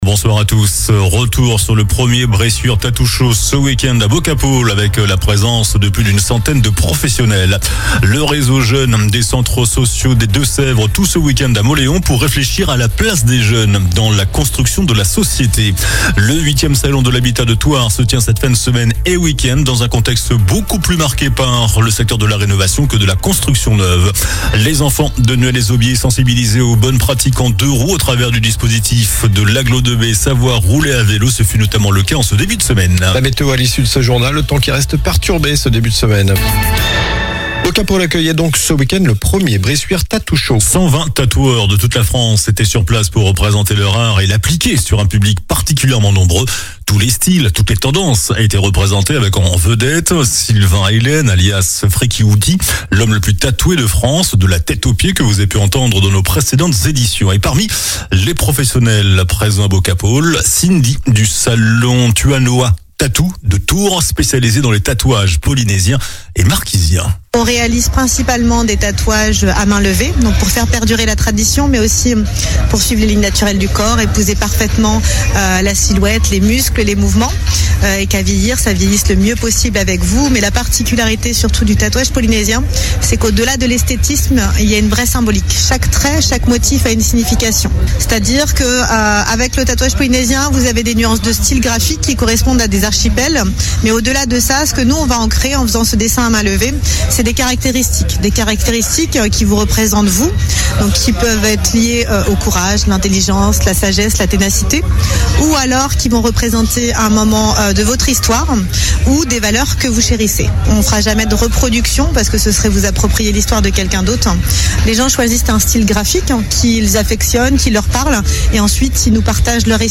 JOURNAL DU LUNDI 12 MAI ( SOIR )